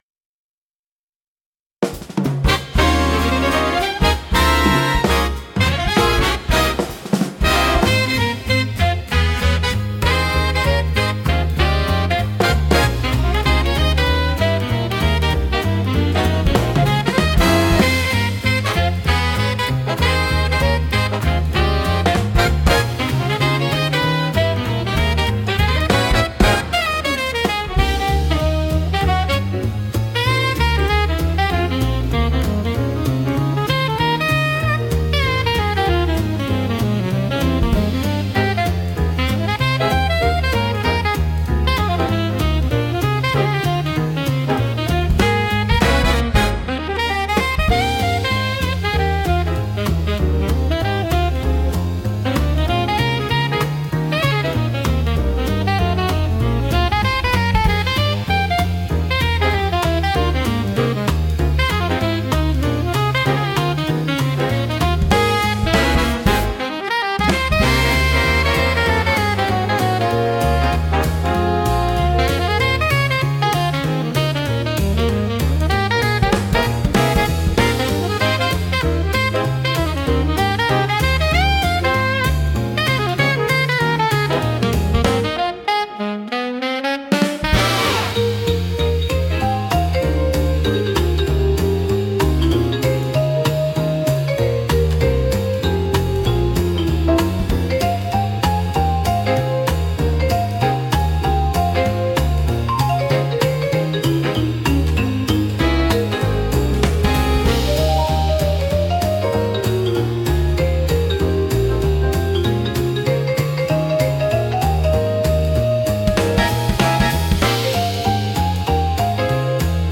聴く人を楽しい気分にさせる迫力とノリの良さが魅力のジャンルです。